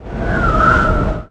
A_GUSTS1.mp3